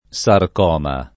sarcoma
sarcoma [ S ark 0 ma ]